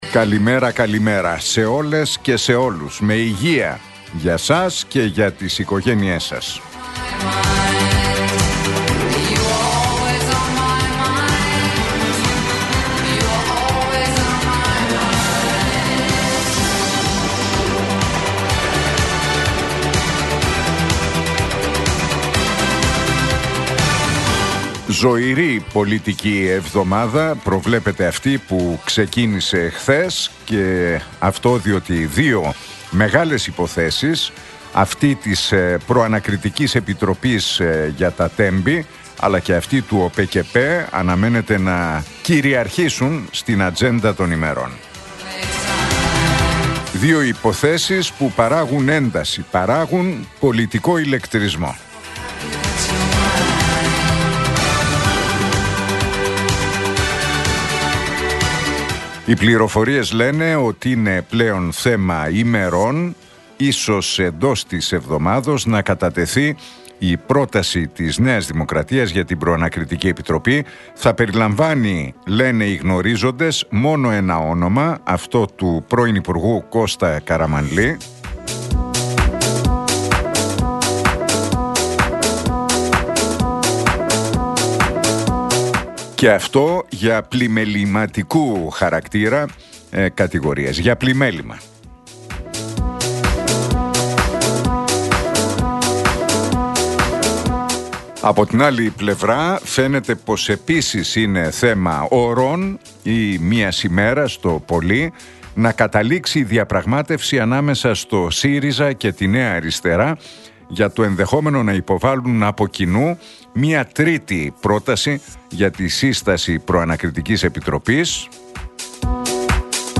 Ακούστε το σχόλιο του Νίκου Χατζηνικολάου στον ραδιοφωνικό σταθμό Realfm 97,8, την Τρίτη 27 Μαΐου 2025.